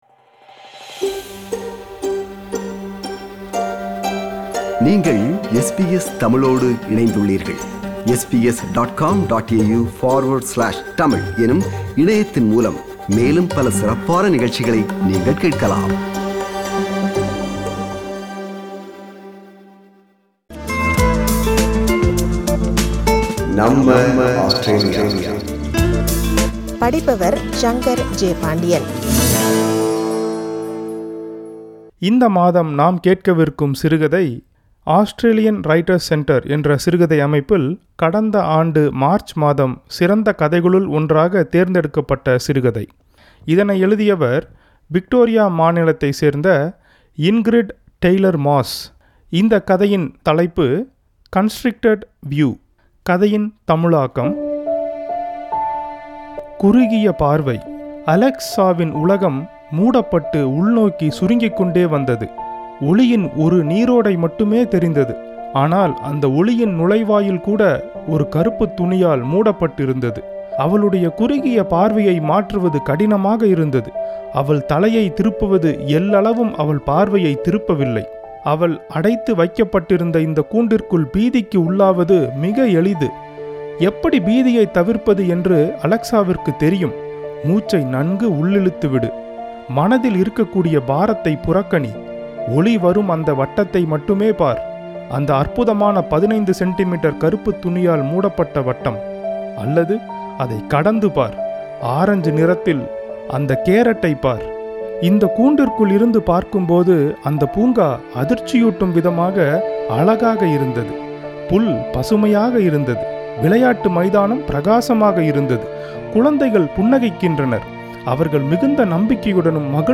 narrates Ingrid Taylor-Moss’s “Constricted View” in Tamil